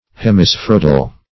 Search Result for " hemispheroidal" : The Collaborative International Dictionary of English v.0.48: Hemispheroidal \Hem`i*sphe*roid"al\, a. Resembling, or approximating to, a hemisphere in form.
hemispheroidal.mp3